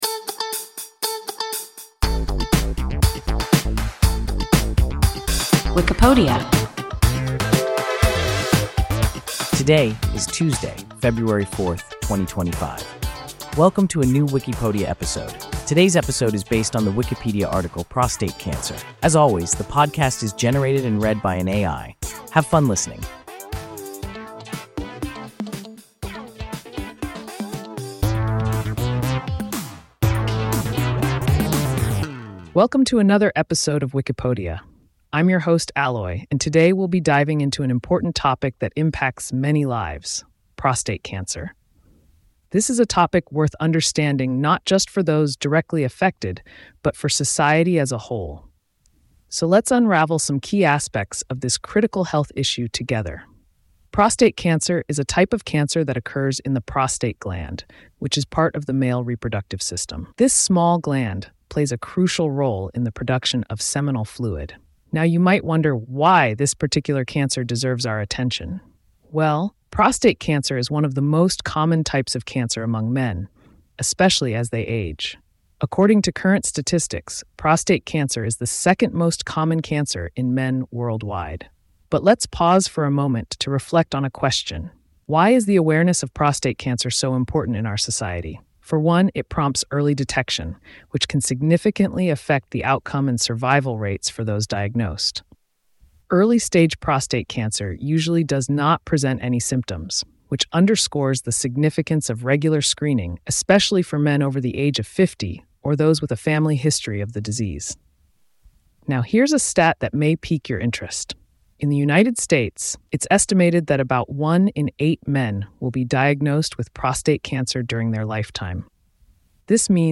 Prostate cancer – WIKIPODIA – ein KI Podcast